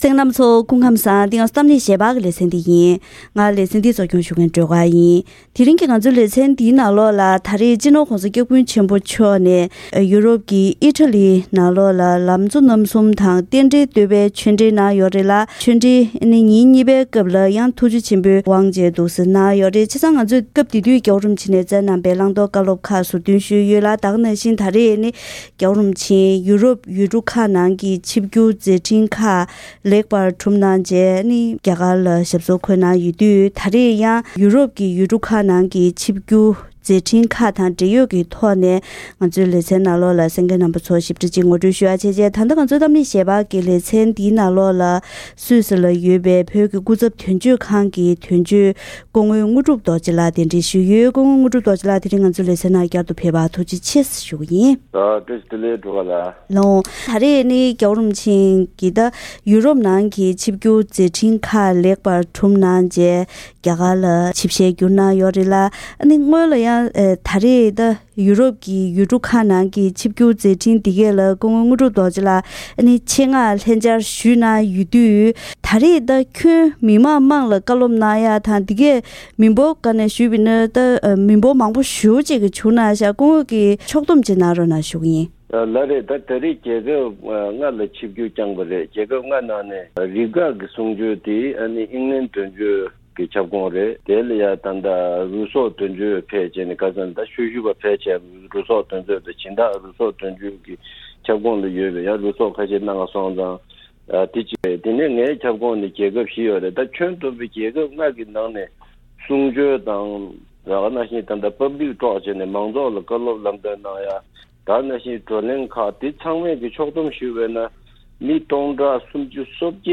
ད་རིང་གི་གཏམ་གླེང་ཞལ་པར་ལེ་ཚན་ནང་སྤྱི་ནོར་༧གོང་ས་སྐྱབས་མགོན་ཆེན་པོ་མཆོག་ནས་ཨི་ཌ་ལིའི་མི་ལན་ལ་གསུང་ཆོས་གནང་སྐབས་སེམས་ཀྱི་ཞི་བདེ་གལ་ཆེ་ཡིན་པ་སོགས་བཀའ་སློབ་གནང་བ་དང་། དེ་བཞིན་ད་རེས་ཡུ་རོབ་ཡུལ་གྲུ་ཁག་ནང་གི་ཆིབས་བསྒྱུར་སྐོར་སུད་སི་དོན་གཅོད་ལྷན་བཀའ་མོལ་ཞུས་པ་ཞིག་གསན་རོགས་གནང་།